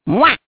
One of Waluigi's voice clips in Mario Kart DS